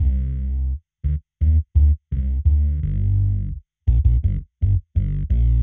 Index of /musicradar/dub-designer-samples/85bpm/Bass
DD_JBassFX_85C.wav